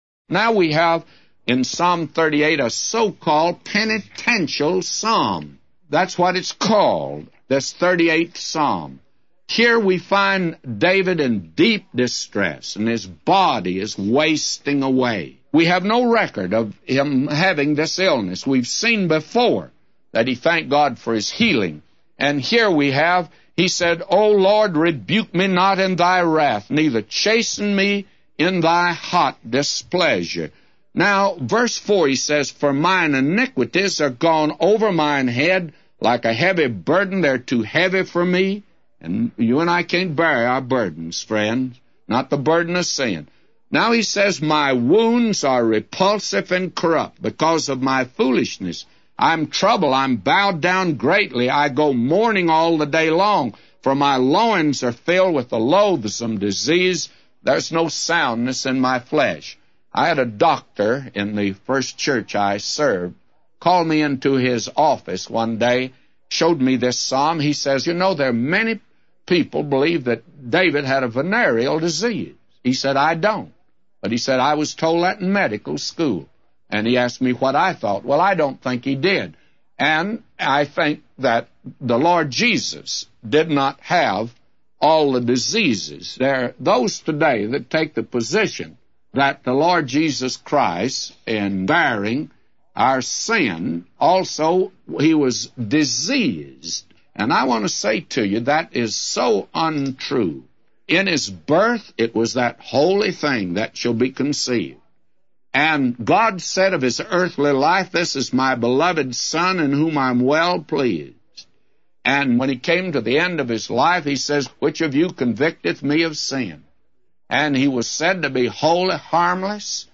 A Commentary By J Vernon MCgee For Psalms 38:1-999